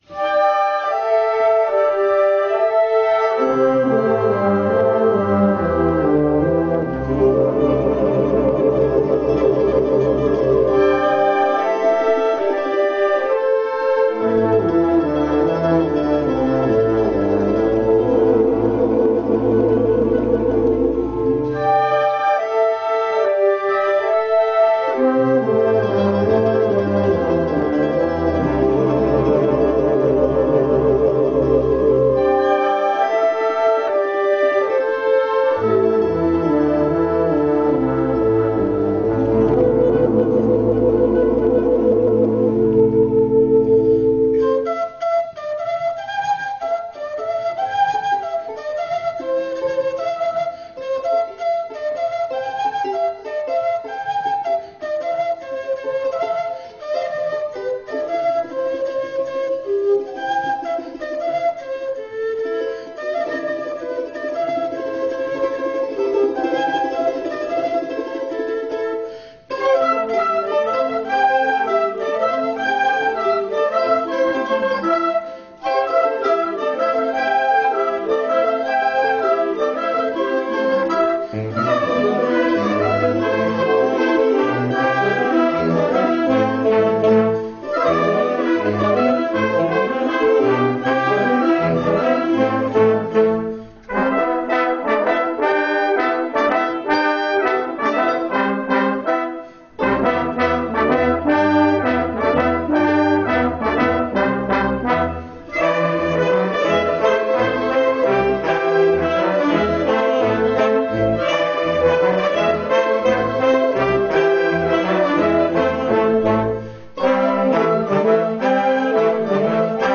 Sur base d’un projet de l’association, la musique « Les trois saisons des Andes », oeuvre de Mario P. GUTIERREZ, a été interprétée par l’Harmonie de Cruseilles – Le Châble accompagnée par les musiciens de l’ensemble RUPHAY en décembre 2013.
Comment la musique des Andes , où règnent flûtes de Pan et charangos, peut-elle être interprétée par une Harmonie où se côtoient trompettes, saxophones, clarinettes, tubas…?